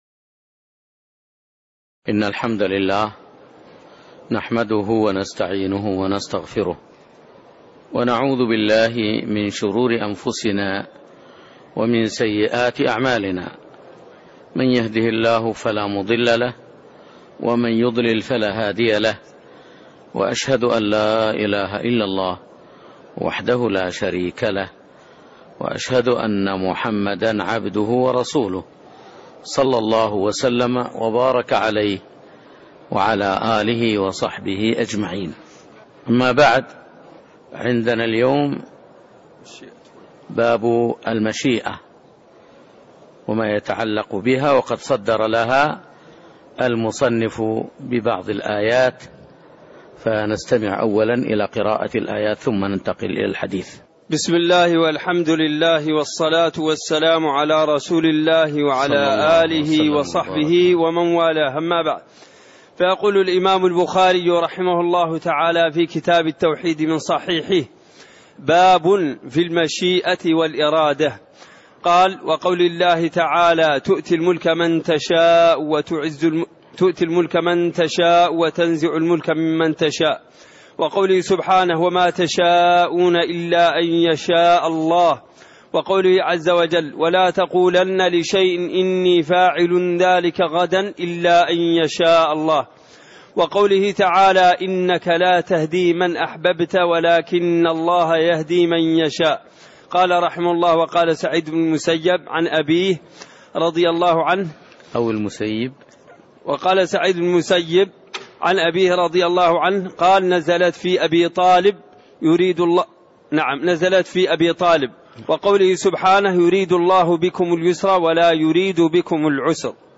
تاريخ النشر ٢٨ محرم ١٤٣٥ هـ المكان: المسجد النبوي الشيخ